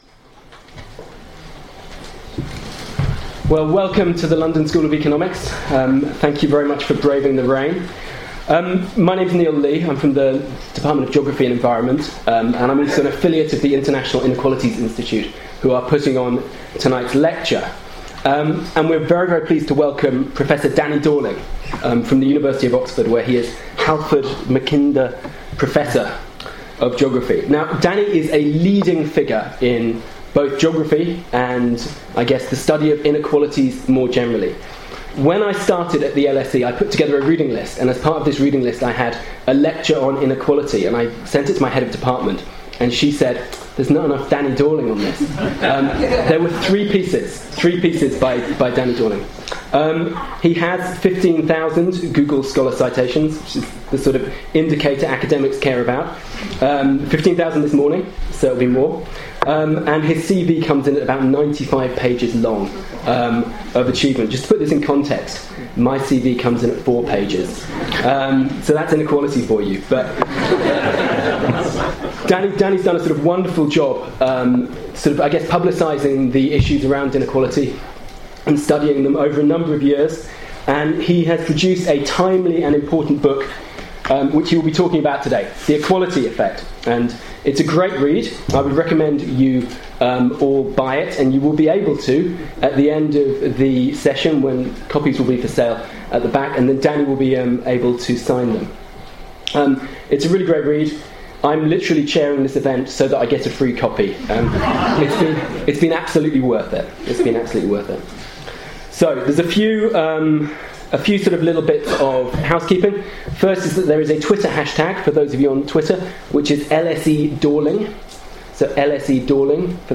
Public Lecture by Danny Dorling, hosted by the International Inequalities Institute, London School of Economics, London, May 18th 2017.